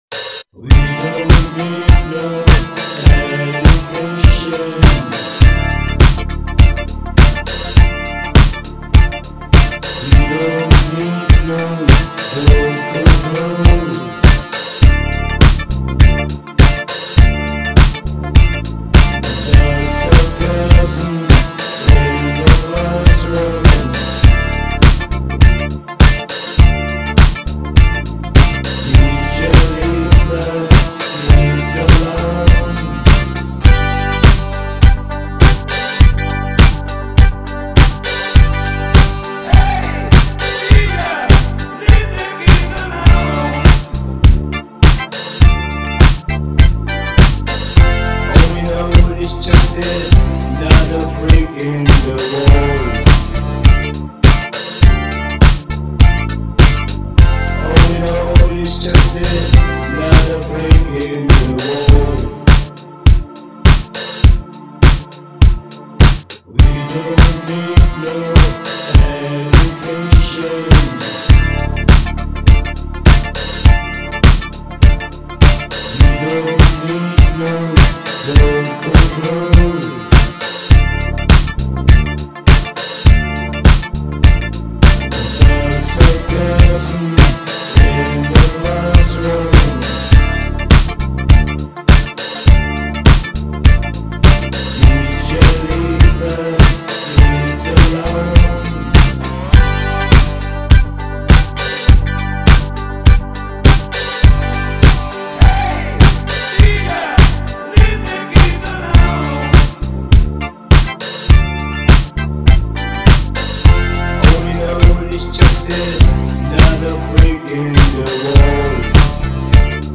rocking